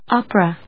音節op・er・a 発音記号・読み方
/άp(ə)(米国英語), ˈɔp(ə)(英国英語)/